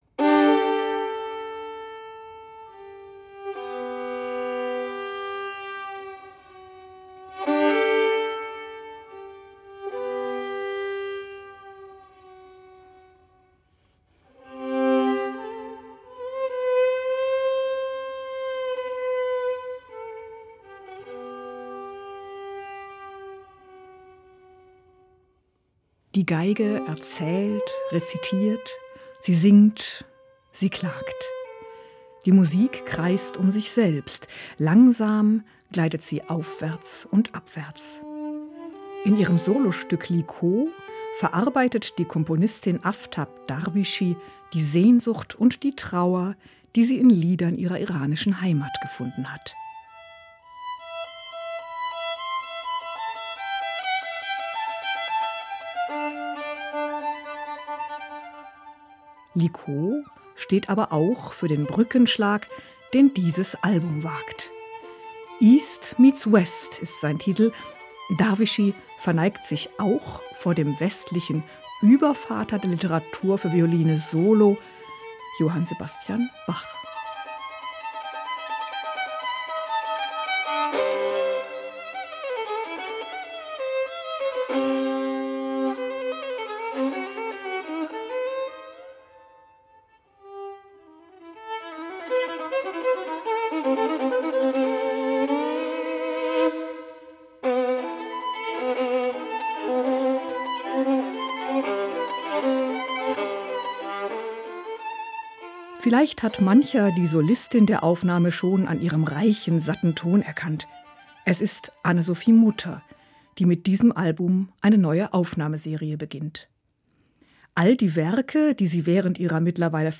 Album-Tipp